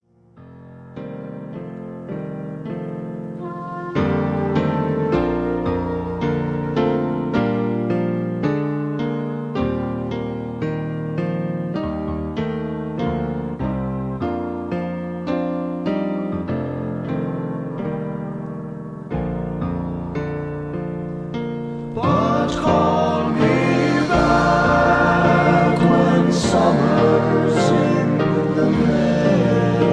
Key-Bb
Tags: backing tracks , irish songs , karaoke , sound tracks